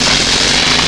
jetgun-1.wav